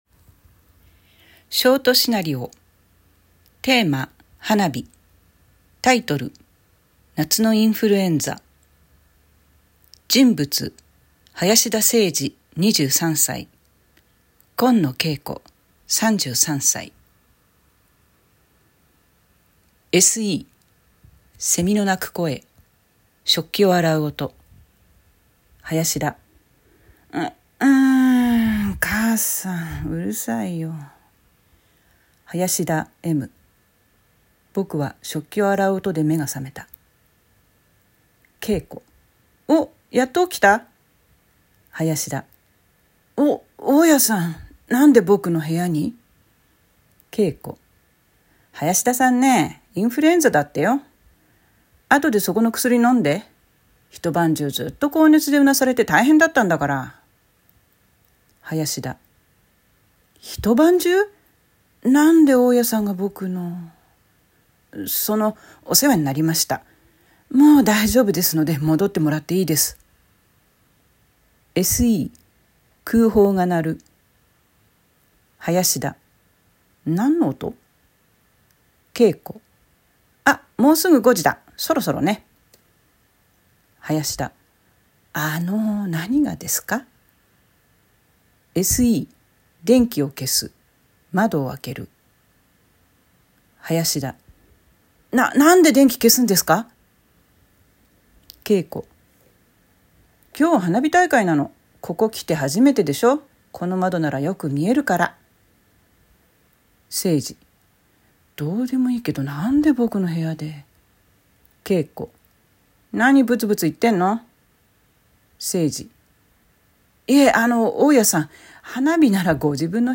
習作朗読『夏のインフルエンザ』
ショートシナリオ